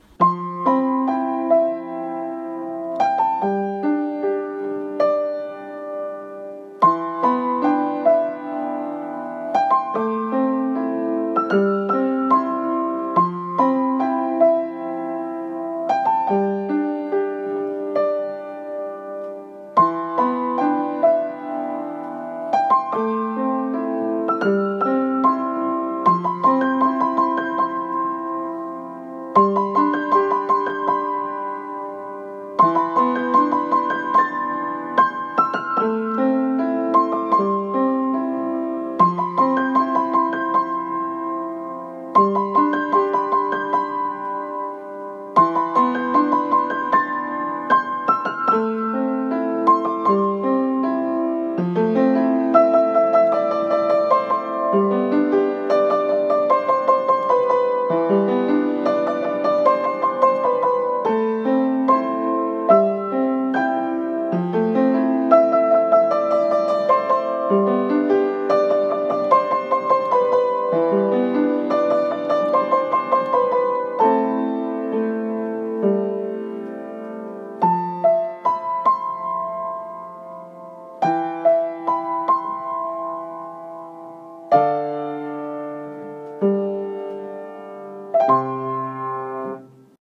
【一人声劇】夫からのビデオレター